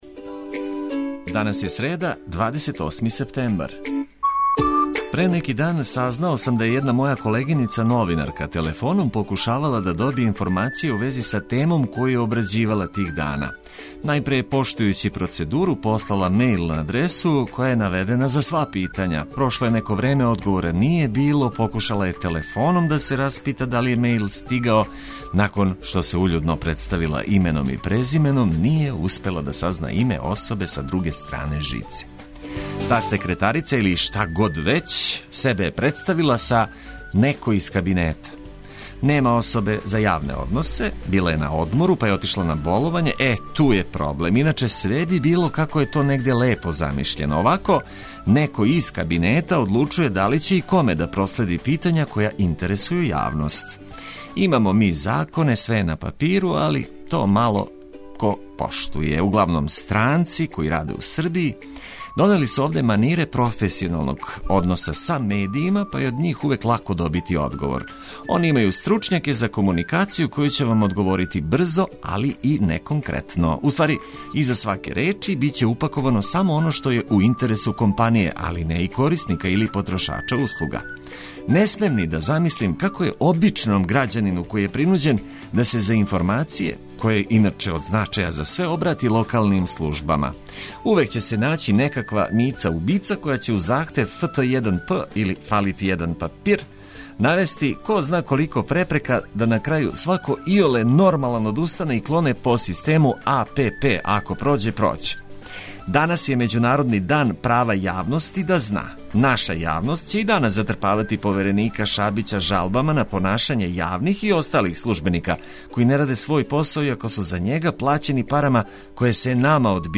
Буђењe усред седмице обележиће корисне информације и најбоља музика за буђење.